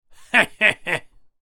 Male Laughing He He He Sound Effect
Description: Male laughing he he he sound effect. A mocking, sneering laugh sound effect perfect for comedy, parody, memes, cartoons, and games. Add a sarcastic or taunting reaction to your videos, TikTok, YouTube, and creative projects with this unique human voice sound effect.
Male-laughing-he-he-he-sound-effect.mp3